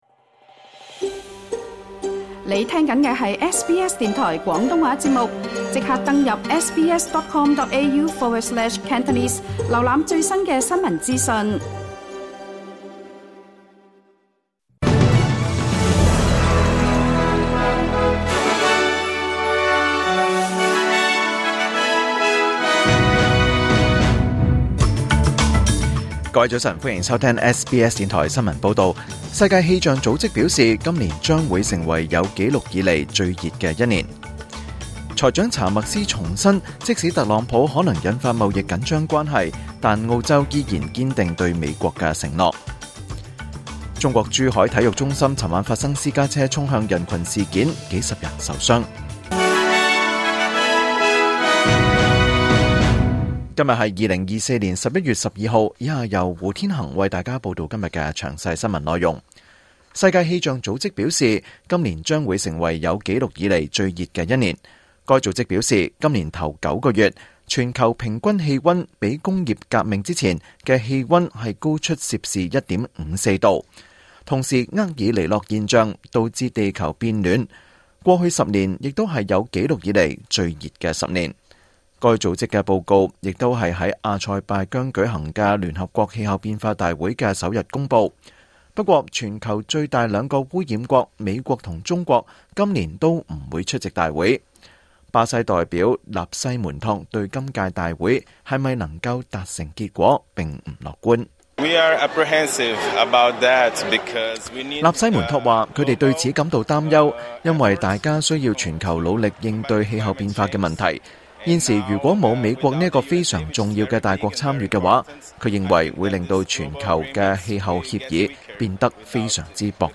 2024年11月12日SBS廣東話節目詳盡早晨新聞報道。